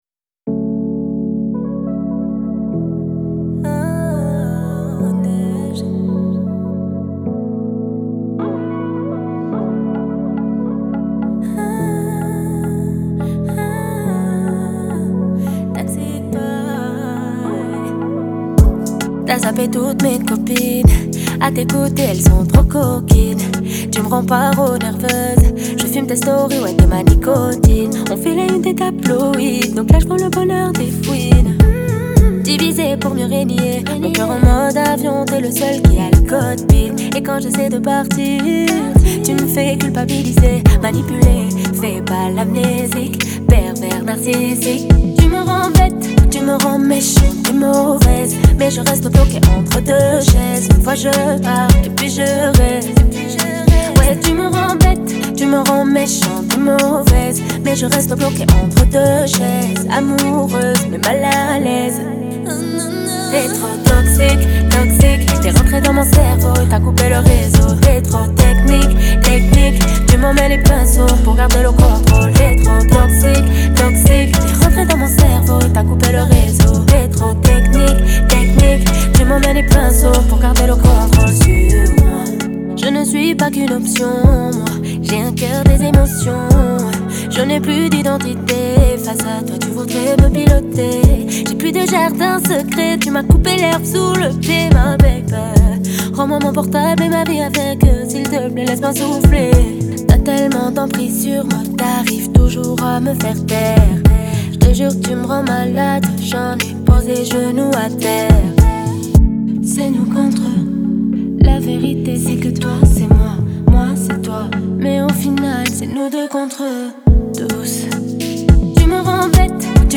french r&b, pop urbaine, french rap Télécharger